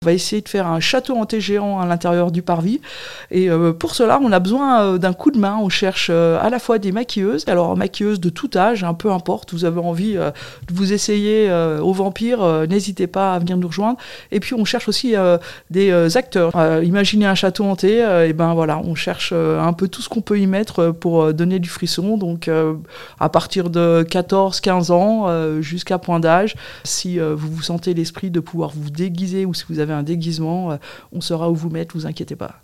Catherine Plewinski, la Présidente du comité des fêtes, était au micro d'ODS Radio.